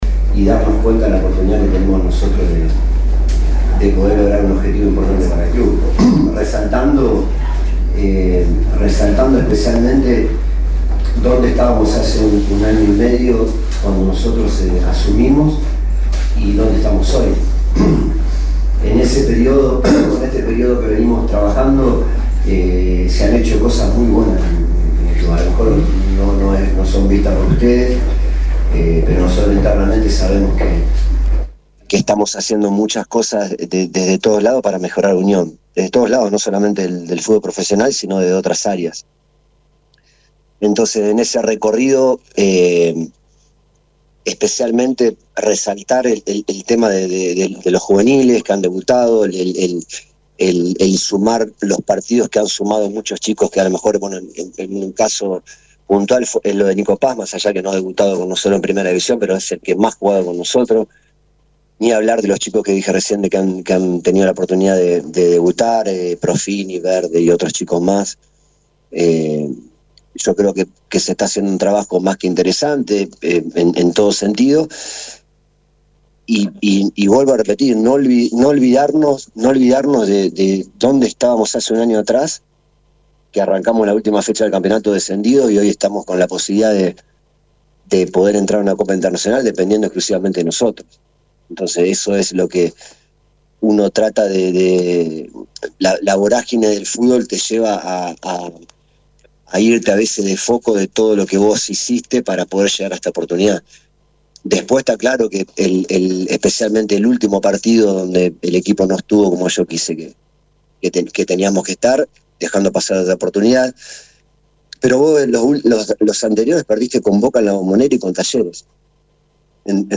Cristian González estuvo al frente de la conferencia que organió el departamento de prensa del Club Atlético Unión. A un día del encuentro ante Vélez el DT se refirió a su continuidad al frente del plantel y a otros puntos importantes que hacen al crecimiento de la entidad rojiblanca.
RED-CONFERENCIA-DT-UNION-KILY-GONZÁLEZ.mp3